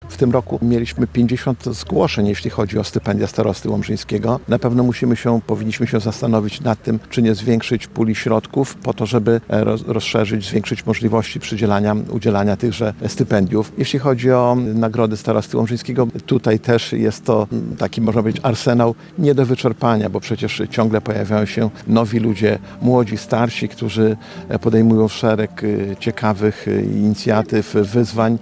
Podczas uroczystej gali w Muzeum Przyrody – Dworze Lutosławskich w Drozdowie wręczono w piątek (12.09) nagrody i stypendia starosty łomżyńskiego.